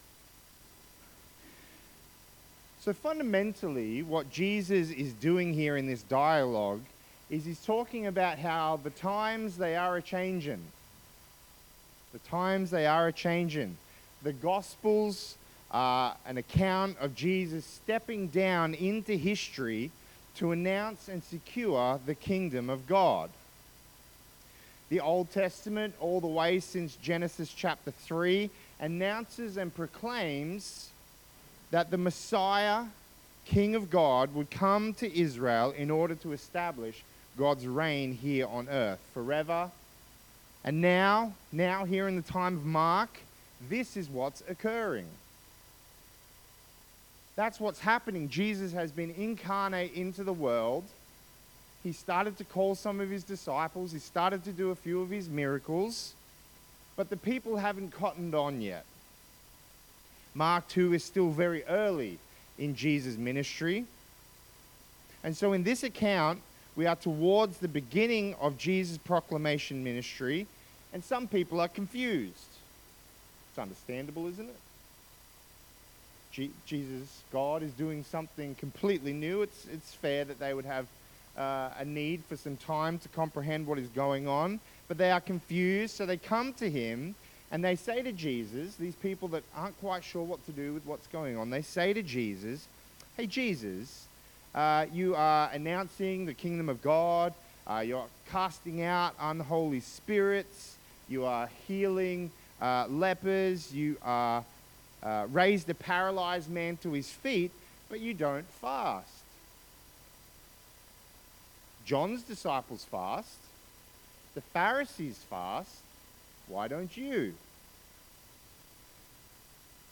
Passage: Mark 2:18-22 Service Type: AM